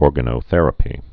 (ôrgə-nō-thĕrə-pē, ôr-gănō-)